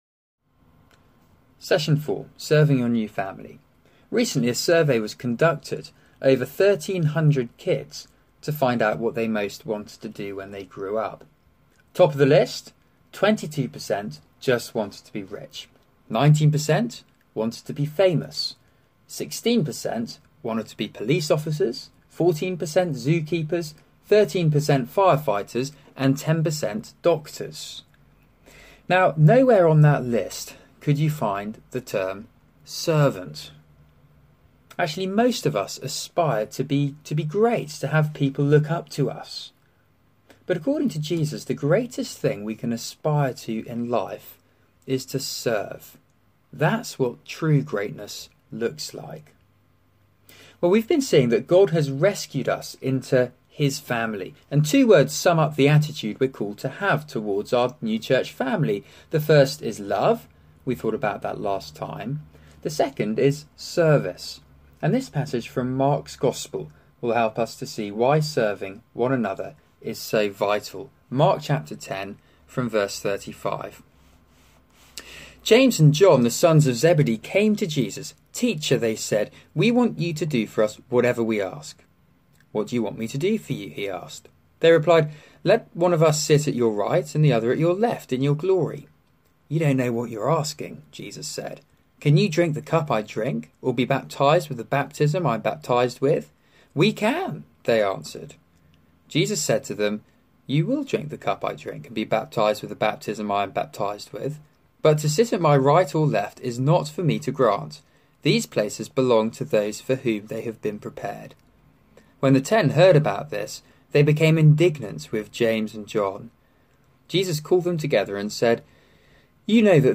Serving your new family Talk